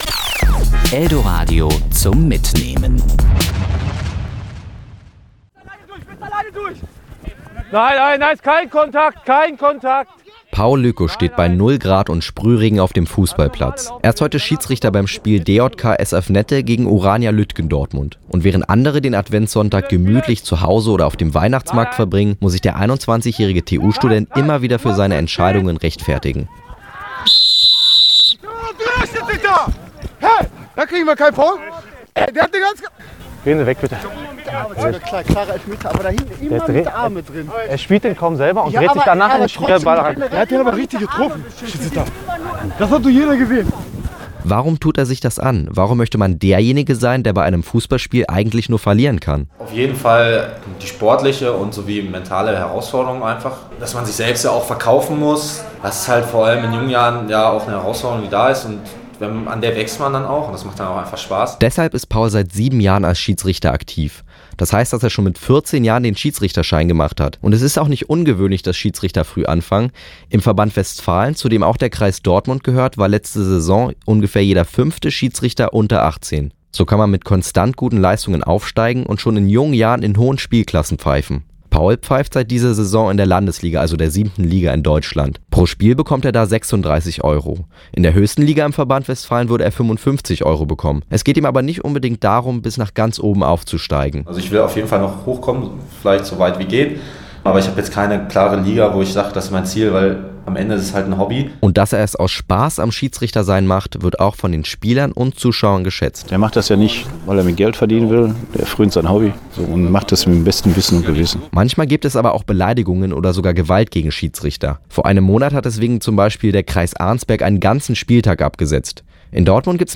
war am Sonntag mit einem Nachwuchs-Schiedsrichter beim Spiel.